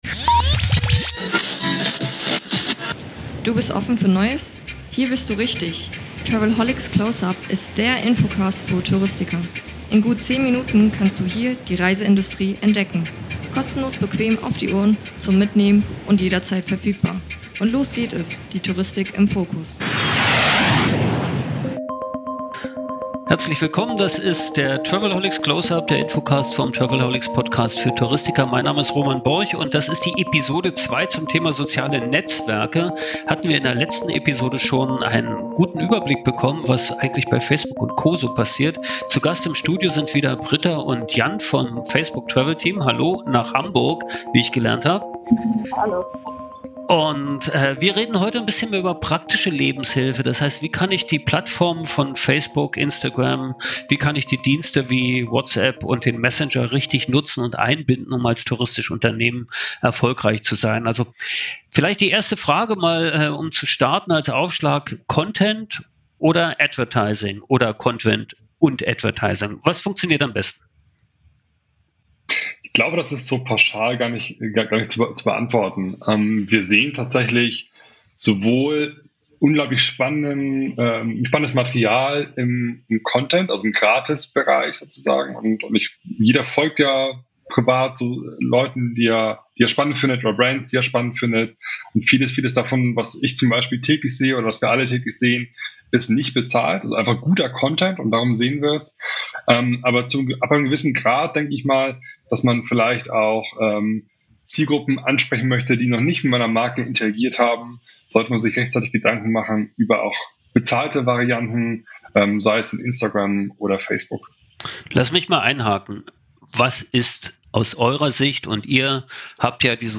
In typischer Travelholics Manier: unkompliziert und spontan.